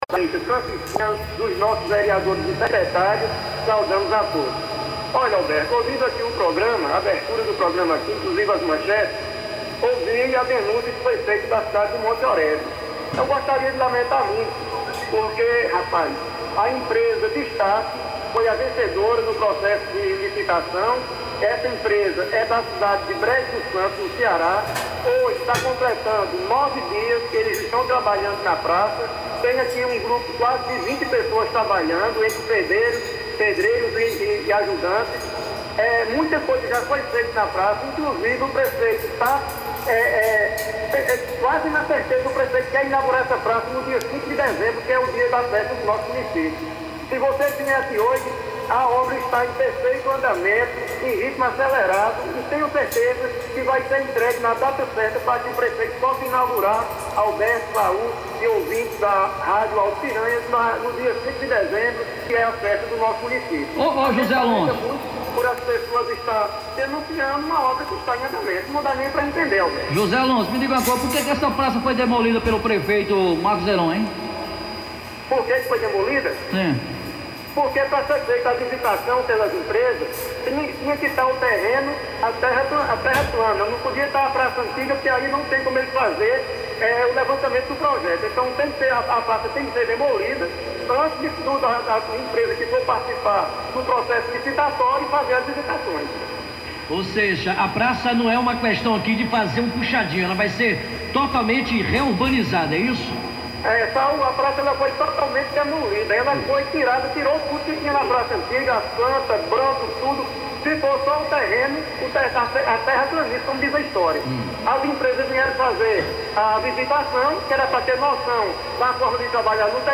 As informações repercutidas aqui pelo Radar Sertanejo foram veiculadas pela Rádio Alto Piranhas da cidade de Cajazeiras.